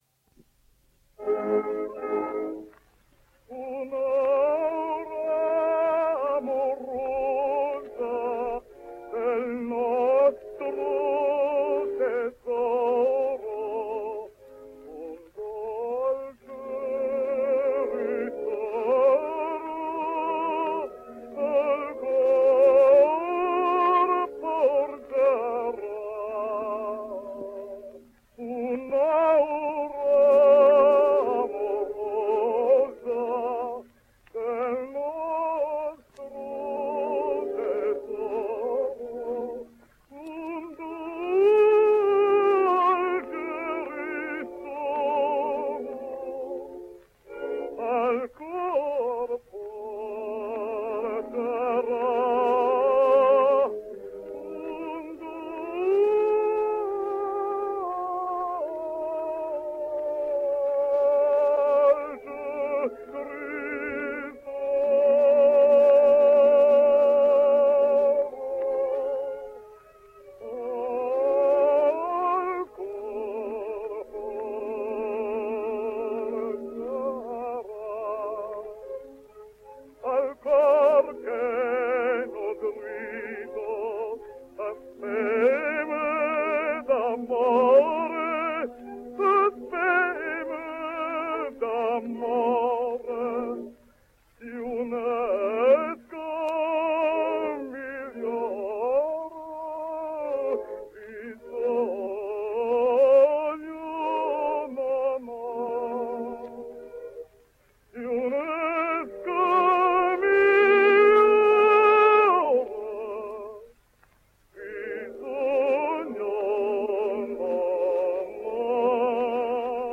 Russian / German Tenor.